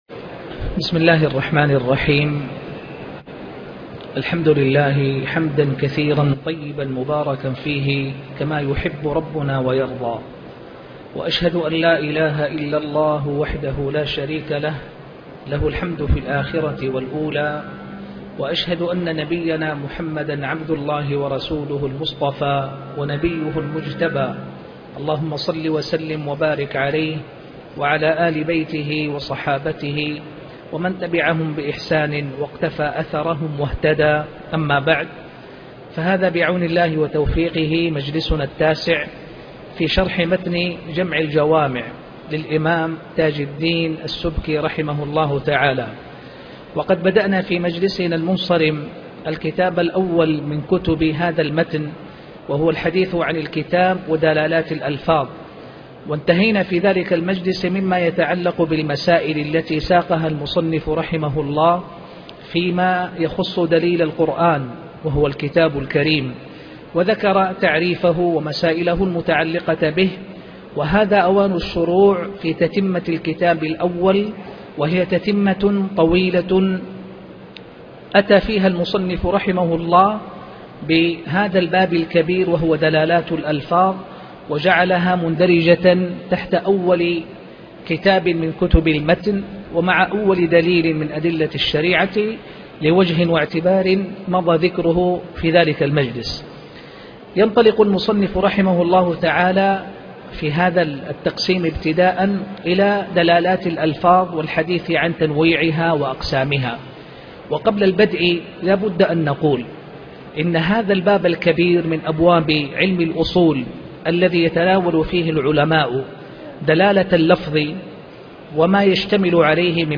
شرح جمع الجوامع الدرس 08 - المنطوق ومفهوم الموافقة